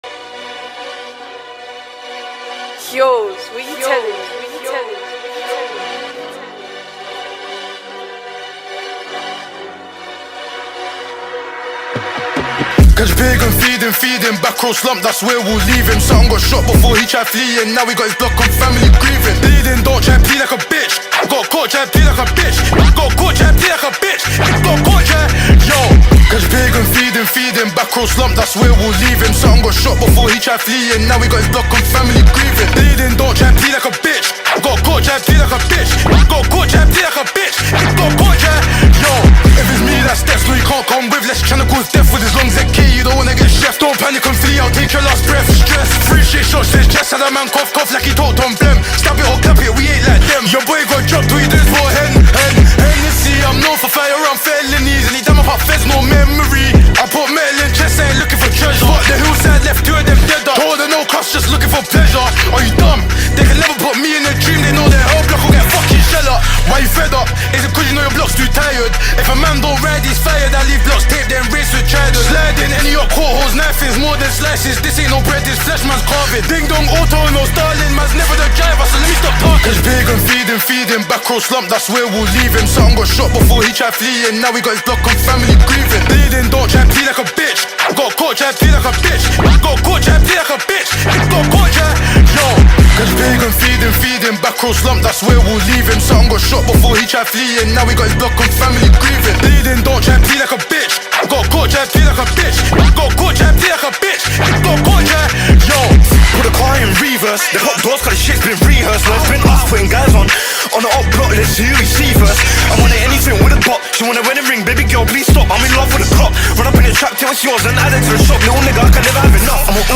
for me it's uk drill